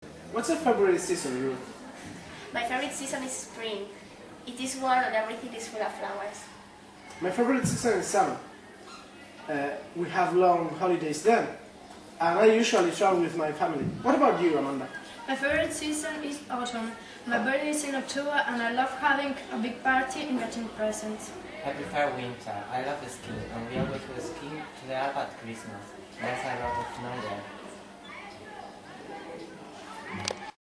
Everyday conversations
Dos chicos y dos chicas sentados en sillas en biblioteca mantienen una conversación